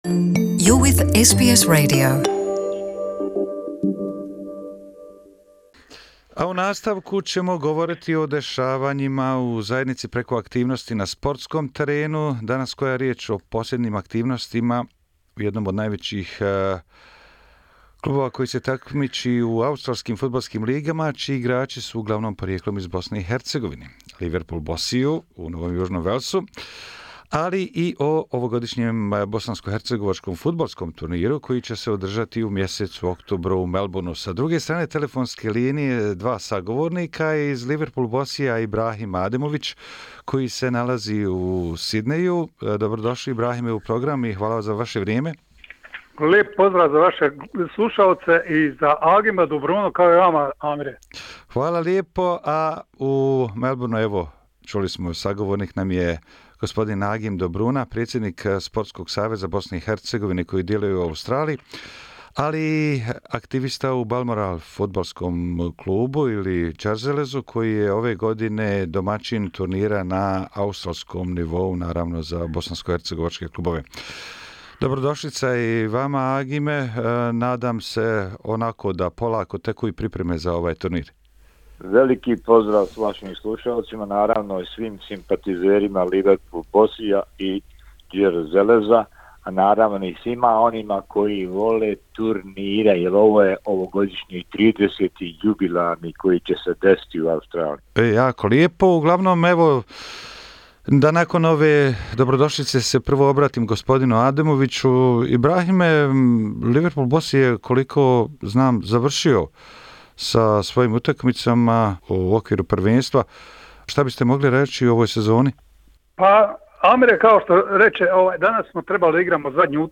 Bossy Liverpool (NSW) je izborio završnicu u play off-u a Balmoral FC (Đerzelez) je za sada drugi na tabeli. U razgovoru